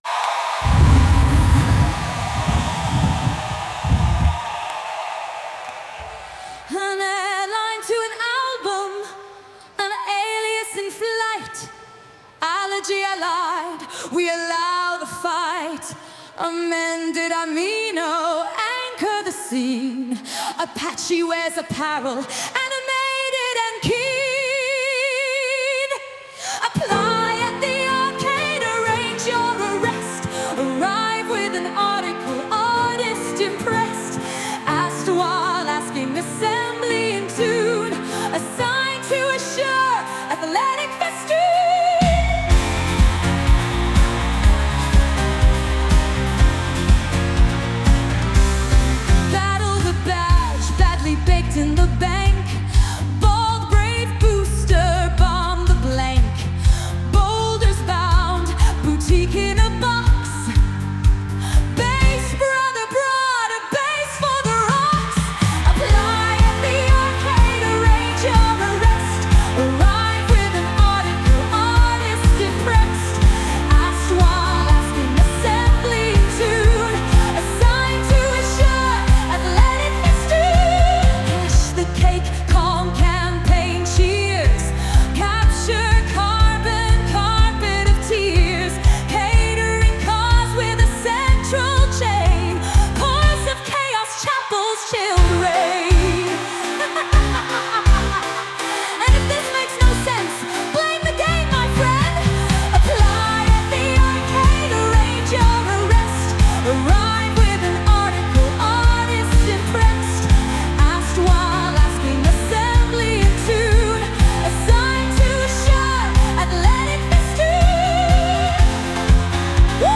Sung by Suno